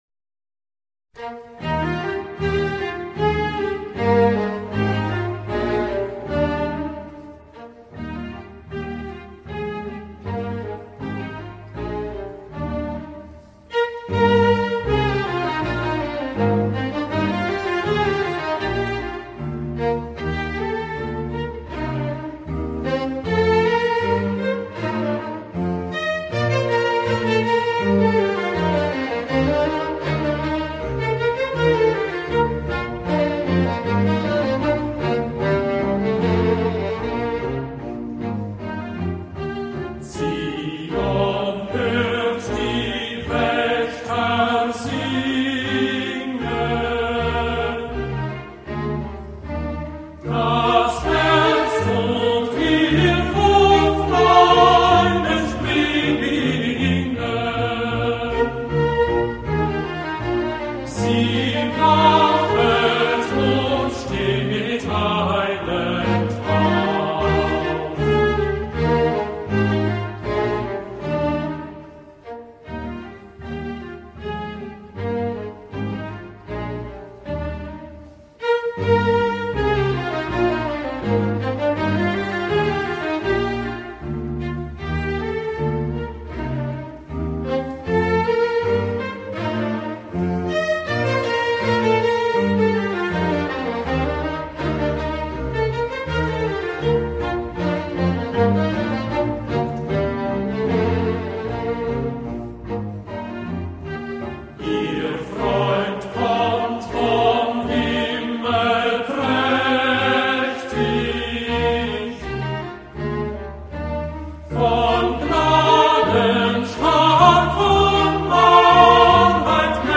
Orgue
0906_Choral_du_veilleur_version_choeurs_Jean-Sebastien_Bach_Orgue.mp3